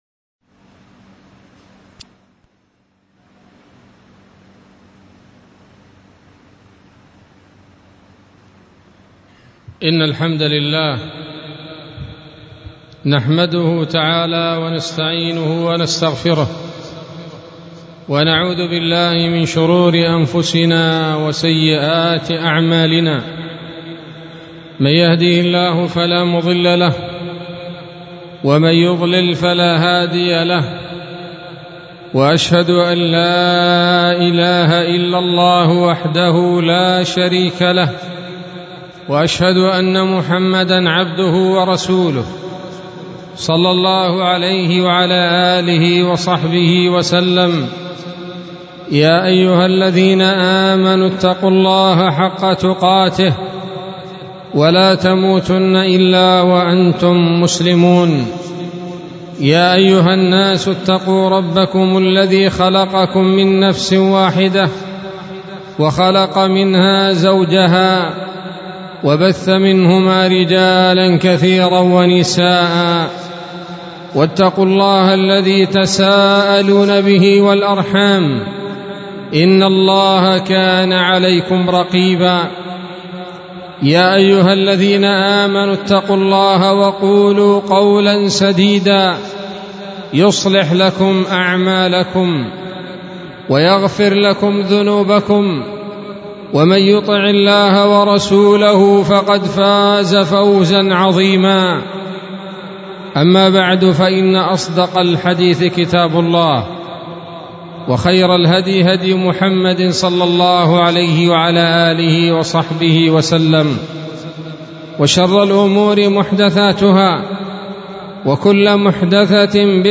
خطبة-الشيخ-في-الجامع-2-ذو-الحجة.mp3